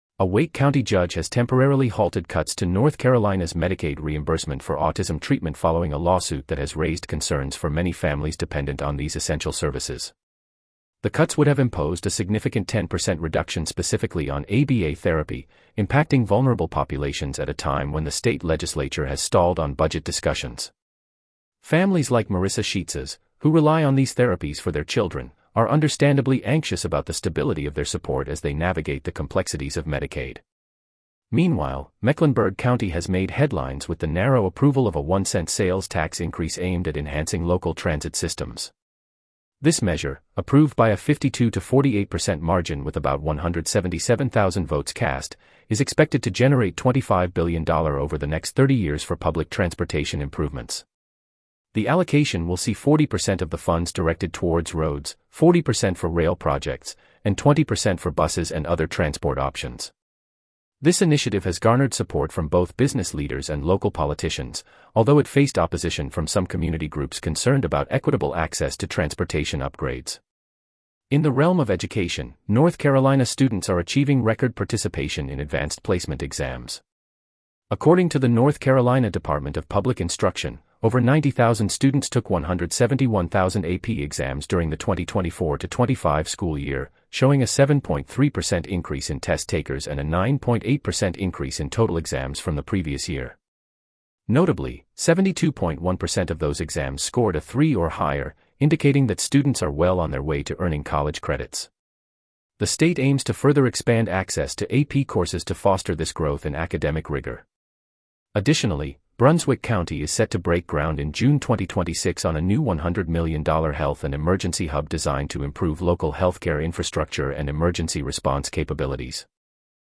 North Carolina News Summary
Regional News